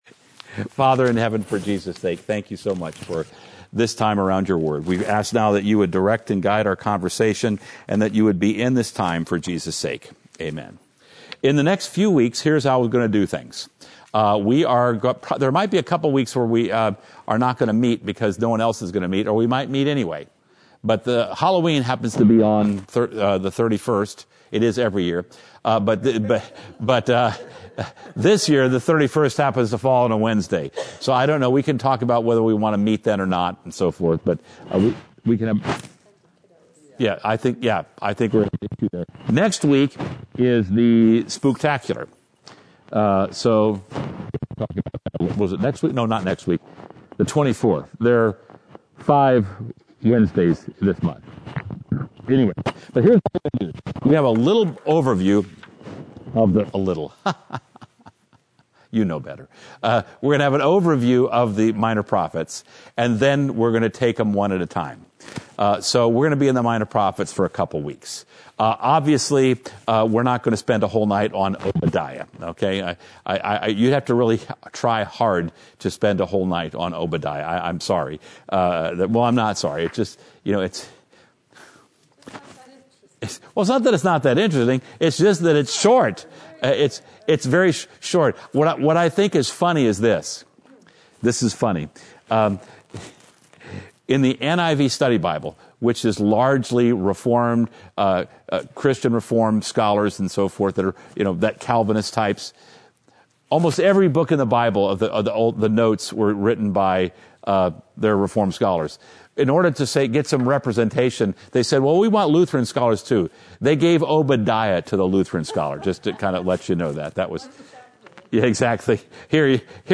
Experience the Word Bible Study